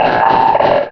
Cri de Kaorine dans Pokémon Rubis et Saphir.